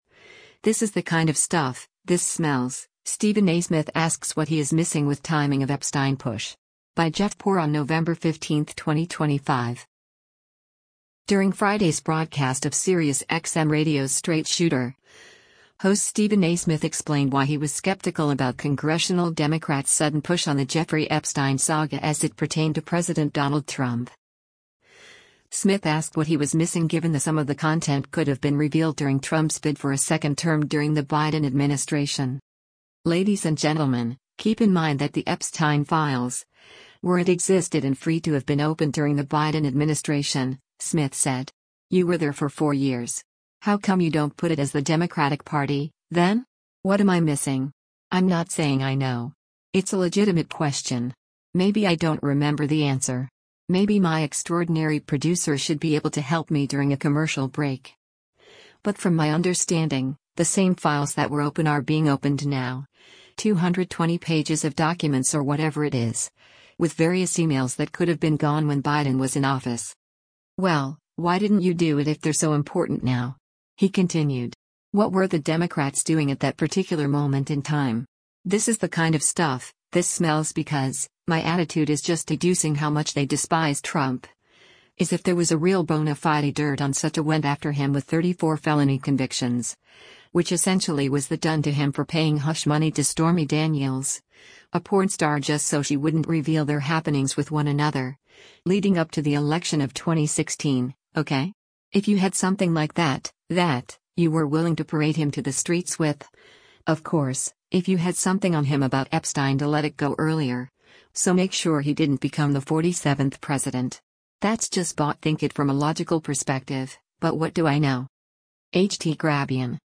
During Friday’s broadcast of SiriusXM radio’s “Straight Shooter,” host Stephen A. Smith explained why he was skeptical about congressional Democrats’ sudden push on the Jeffrey Epstein saga as it pertained to President Donald Trump.